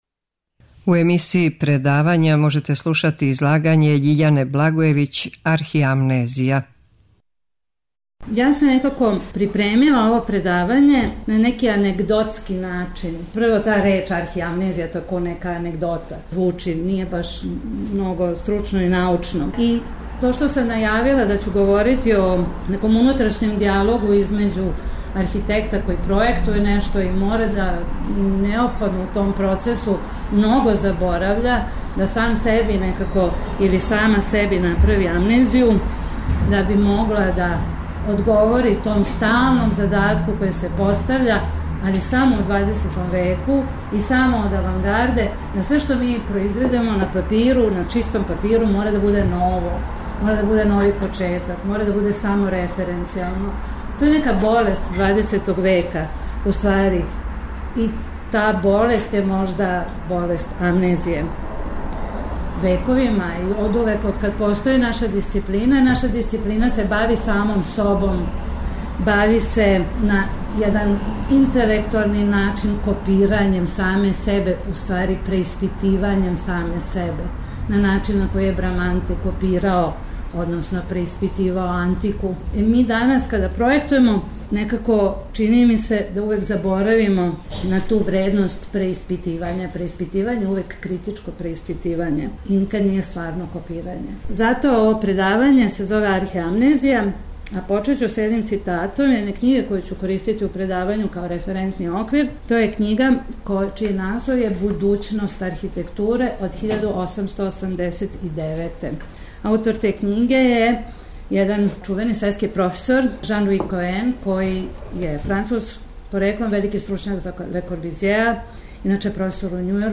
Предавања
Предавање је одржано 20. октобра 2016. године у Кући људских права у оквиру циклуса о „Забораву и заборављенима” који организује Радничка комуна ЛИНКС.